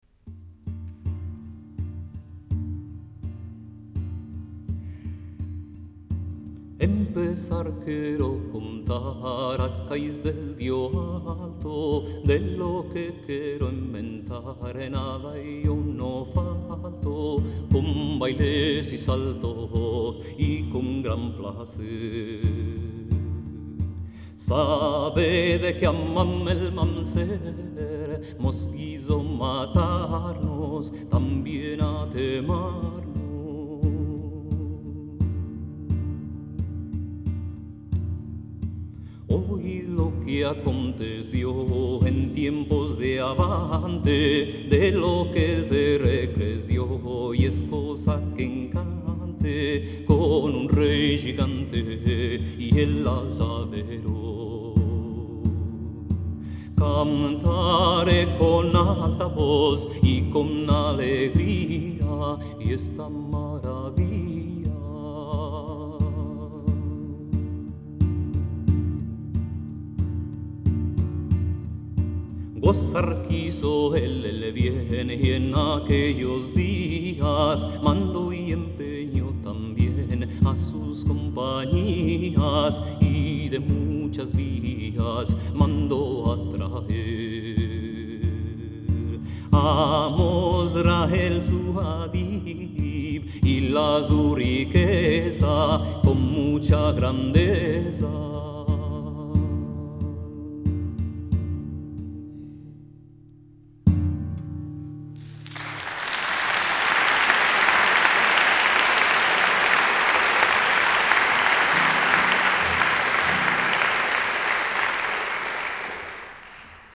Guitarra y voz